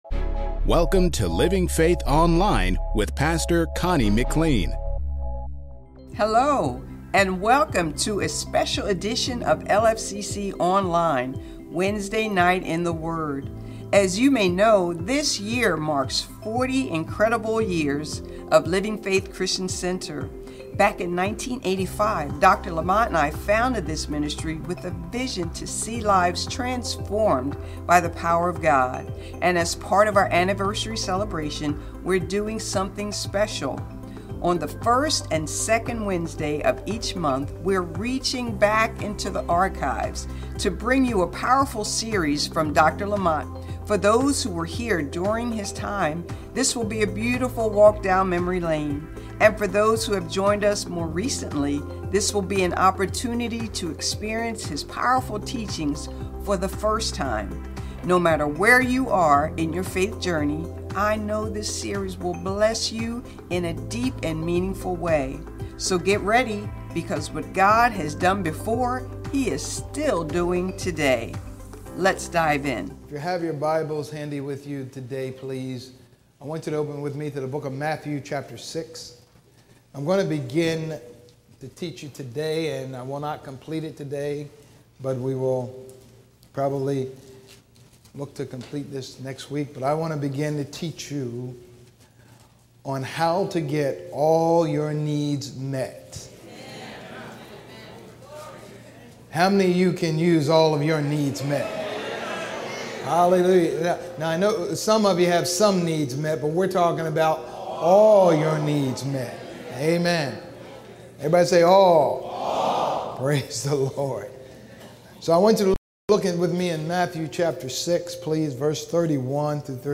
Sermons | Living Faith Christian Center